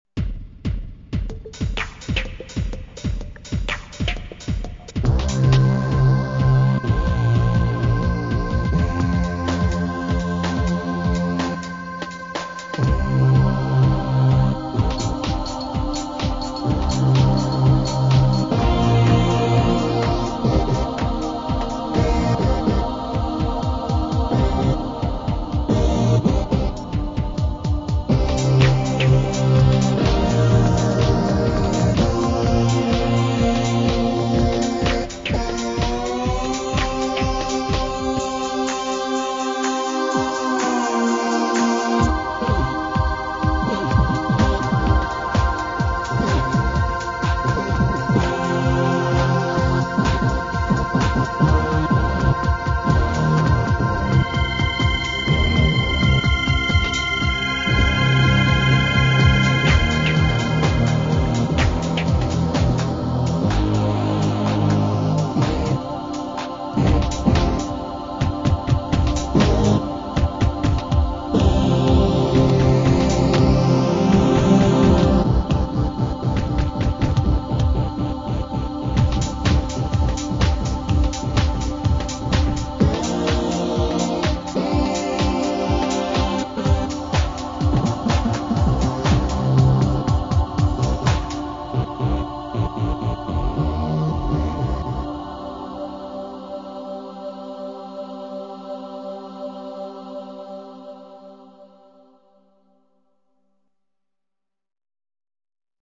As I said, it isn't a bad idea to sit down and do a appealing digital rythm track with the aid of computers (and play it with bass control turned up in a (virtual ?) large space) so I engaged in it myself, and made for instance
this little housemusic piece
or techno or what is it, you tell me, if you dare or feel like it.
I made it with the demonstration version of Cubase and some free plugins anyone could grab from the web, nothing illegal even. One of them being an analog synth emulation. The drums are from the demo of IK multimedia's sampletank.
house2.mp3